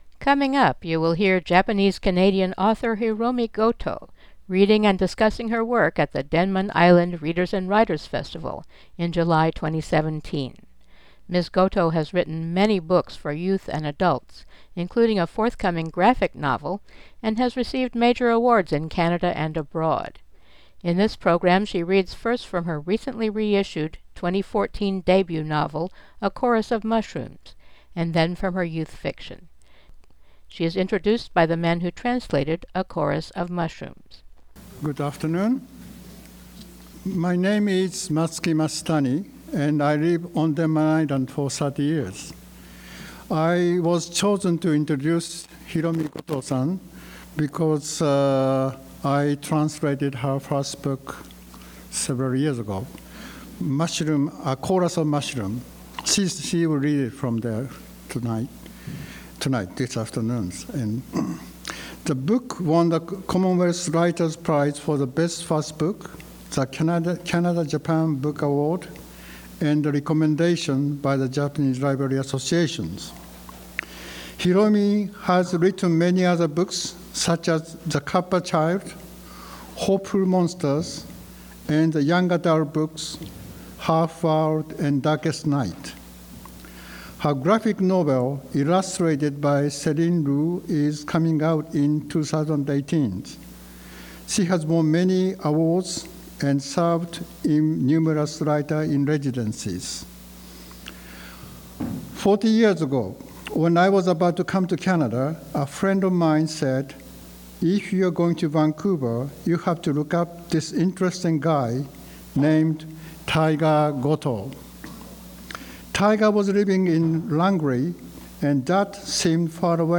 Hiromi Goto solo author reading
at Denman Island Readers & Writers Festival 2017